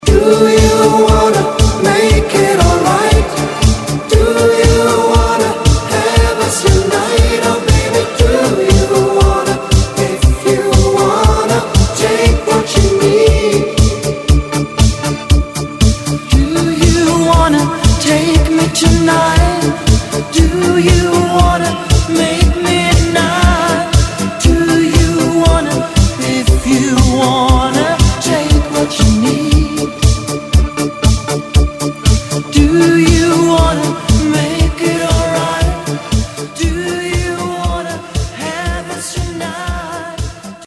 Диско , Поп
Ретро